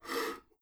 scrape1.wav